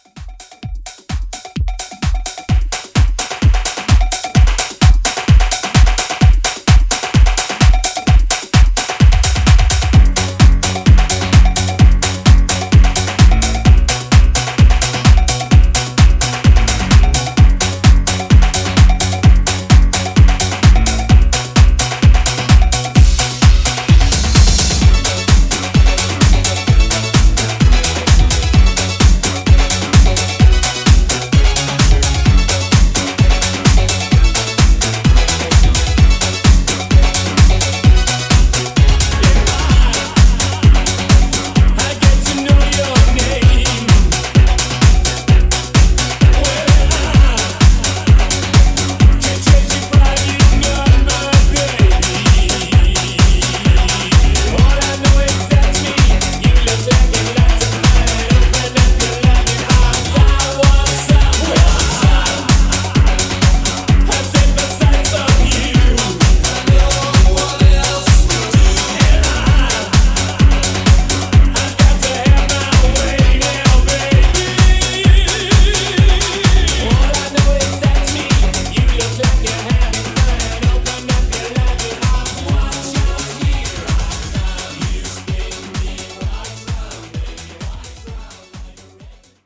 Genre: 90's Version: Clean BPM: 100 Time